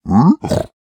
1.21.5 / assets / minecraft / sounds / mob / piglin / idle5.ogg